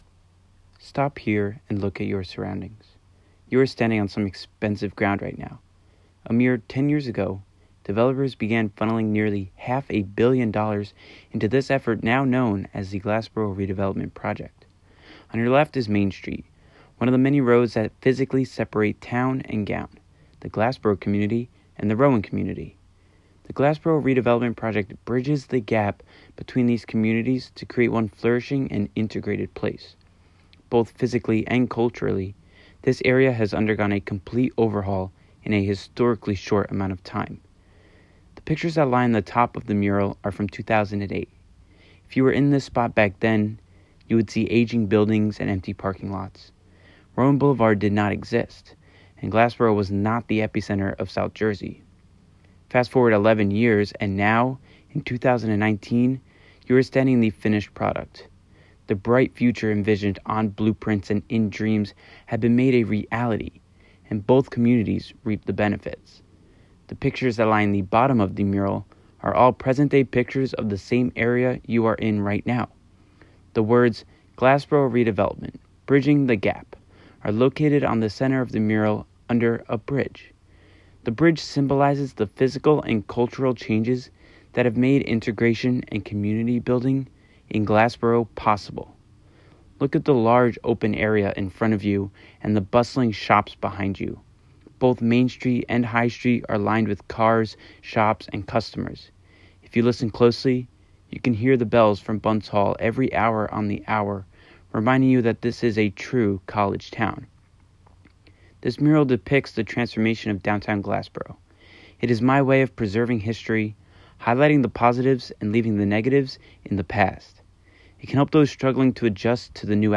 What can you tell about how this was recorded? Use the audio clip above to illustrate the Town Square in Glassboro today.